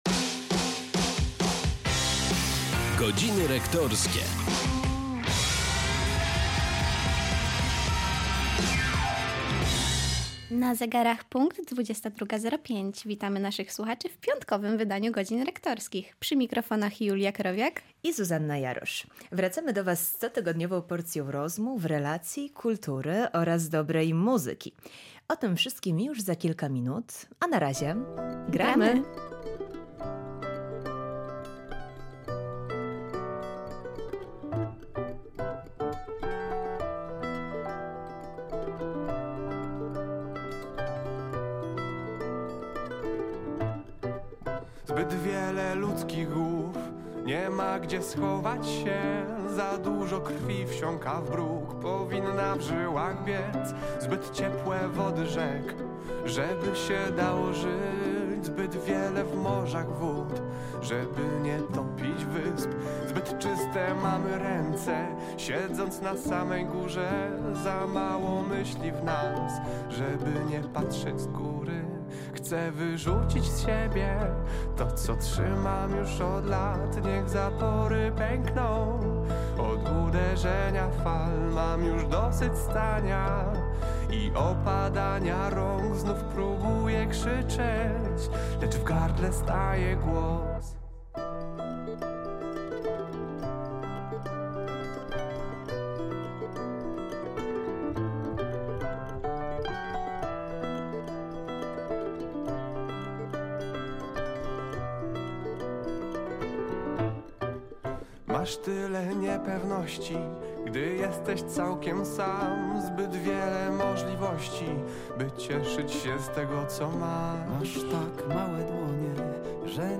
Z energią i uśmiechem, przy akompaniamencie dobrej muzyki